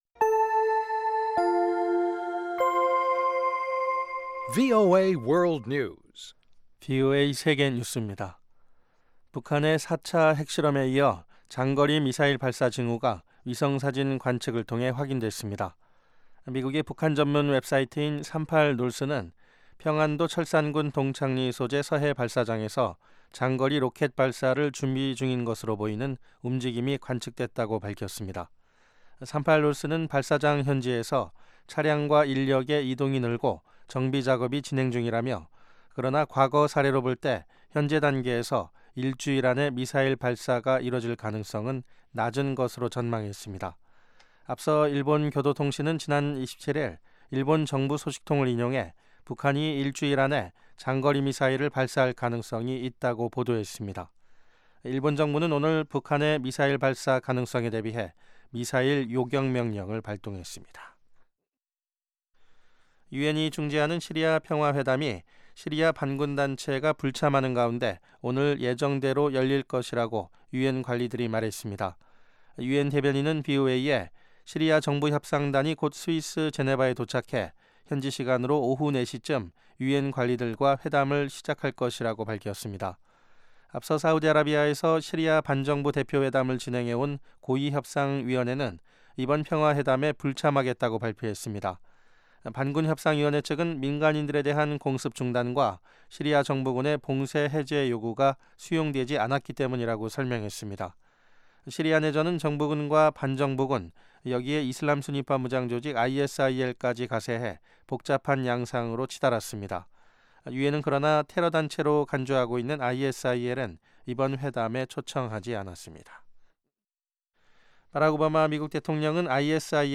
VOA 한국어 방송의 간판 뉴스 프로그램 '뉴스 투데이' 3부입니다.